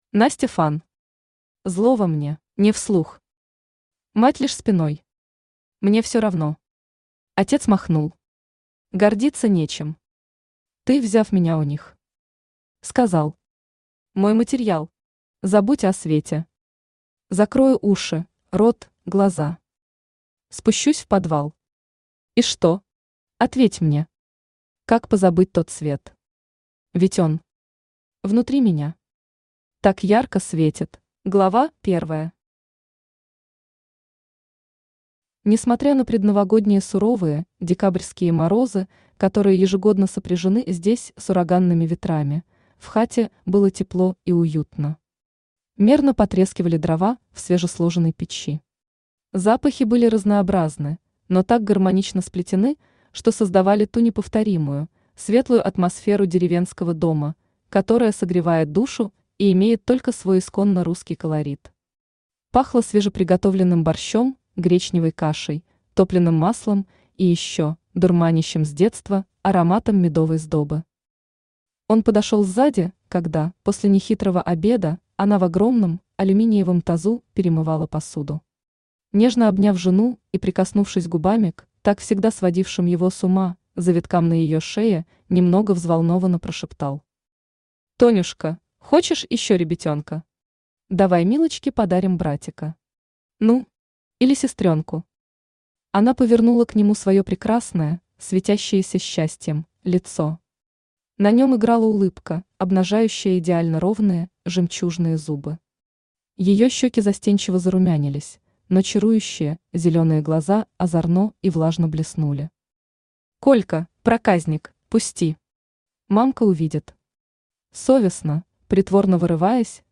Аудиокнига Зло во мне | Библиотека аудиокниг
Aудиокнига Зло во мне Автор Настя Фан Читает аудиокнигу Авточтец ЛитРес.